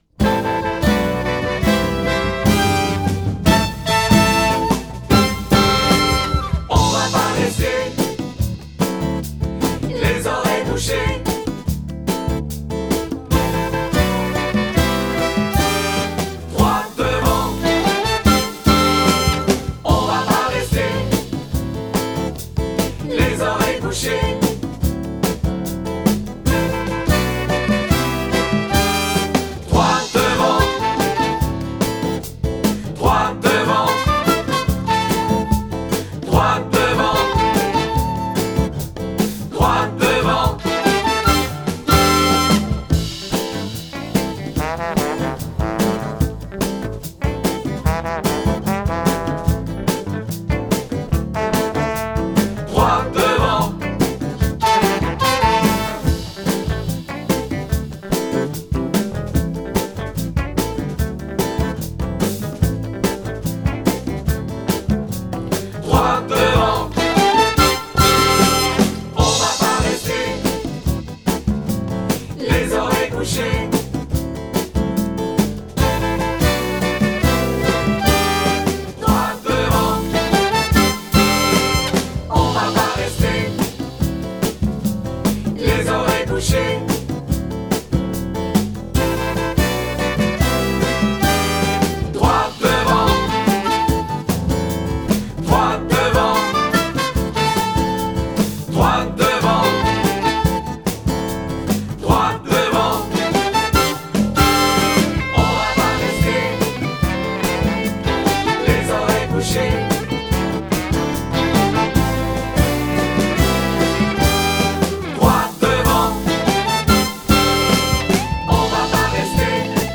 Versions instrumentales téléchargeables